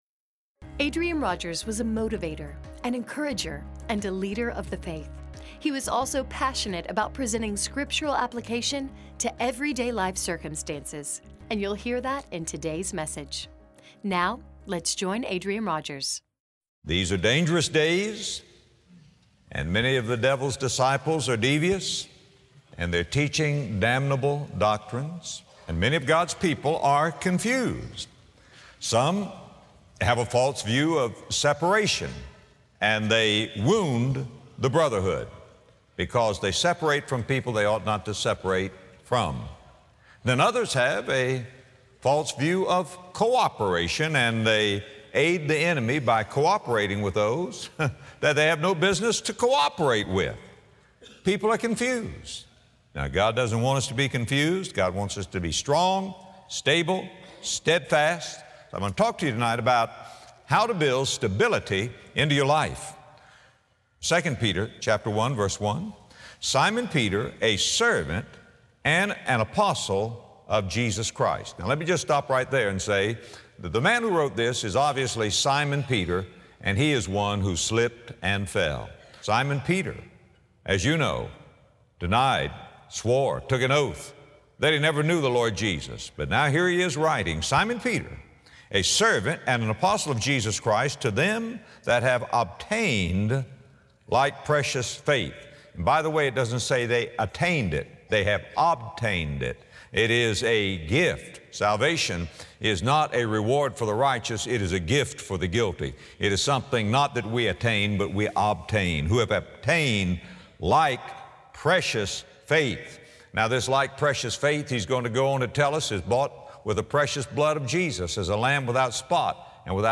Some become strong, vibrant, and victorious in their faith while others stumble and stagger, slip and fall. In this message, Adrian Rogers explains how to build stability into our lives.